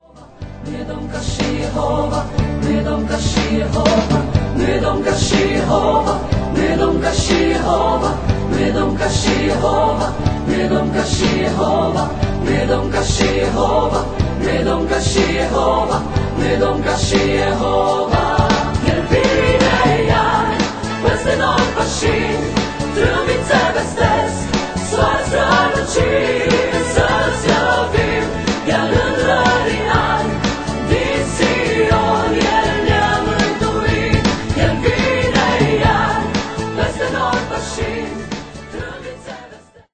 Este un adevarat compendiu de lauda si inchinare.